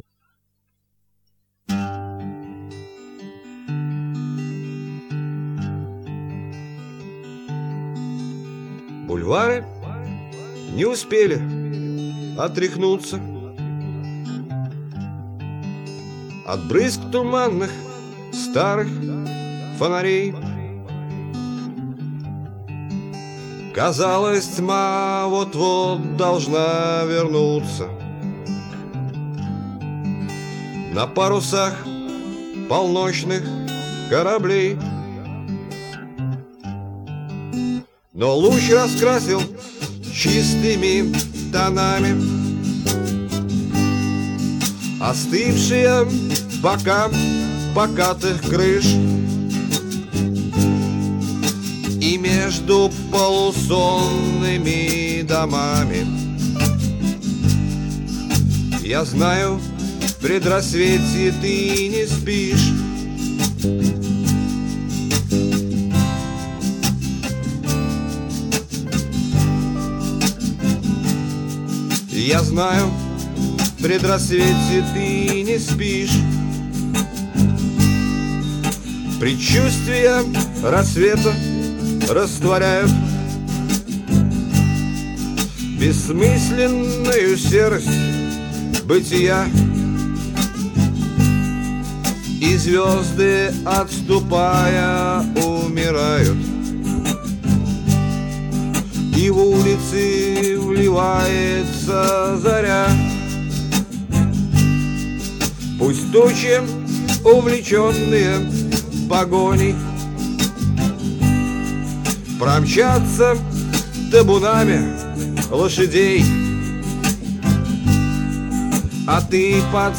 (микрофон висел на кипятильнике :)